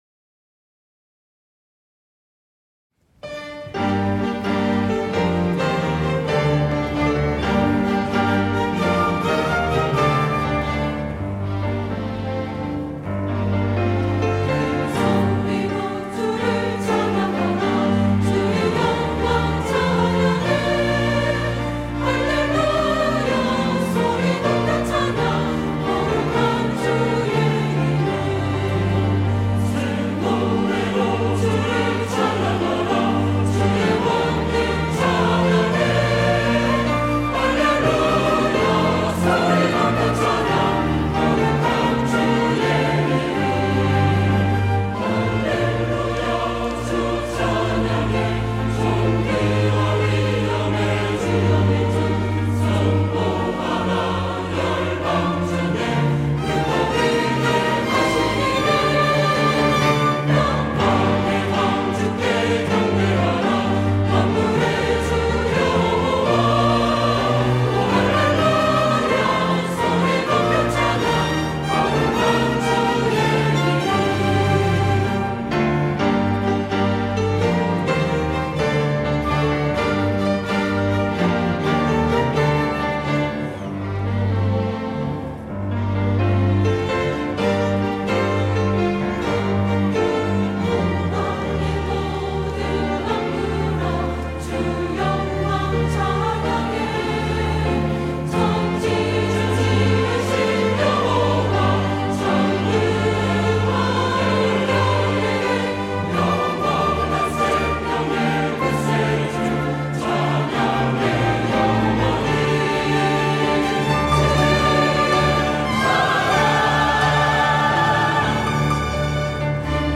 호산나(주일3부) - 할렐루야 주 찬양
찬양대